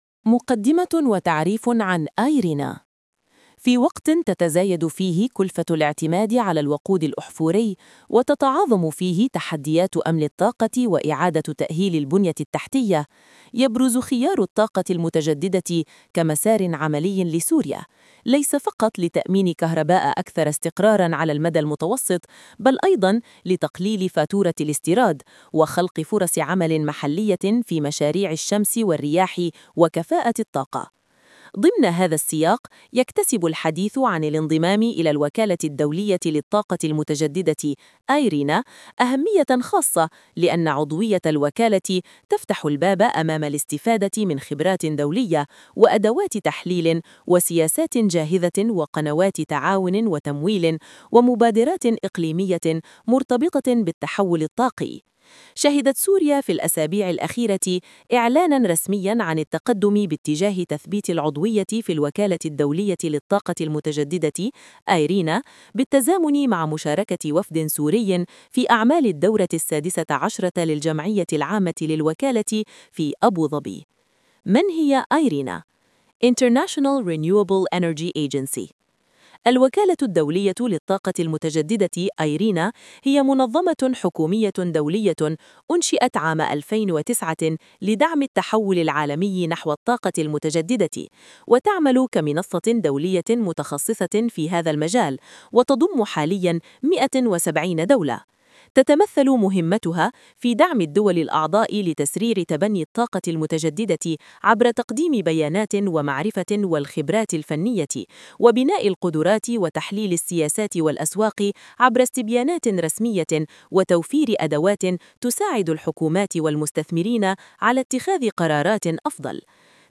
Voice-overt-for-the-IRENA-article.wav